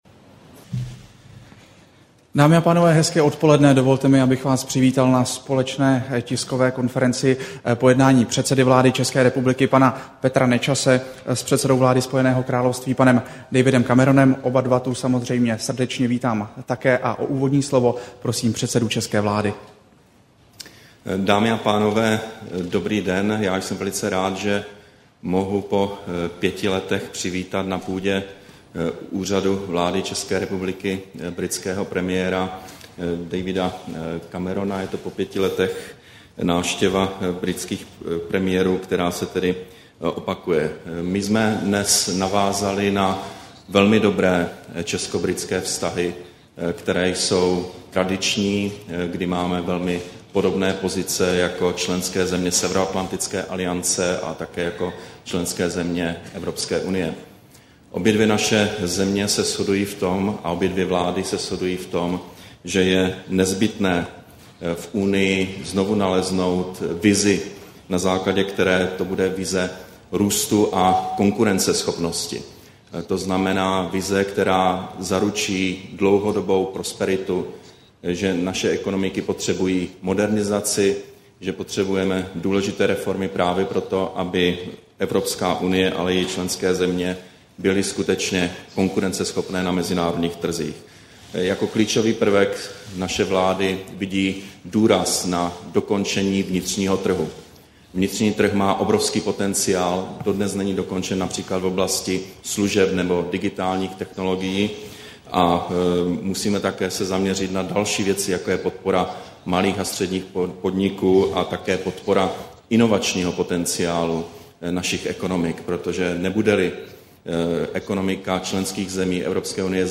Tisková konference po setkání předsedy vlády ČR Petra Nečase s britským předsedou vlády Davidem Cameronem, 23. června 2011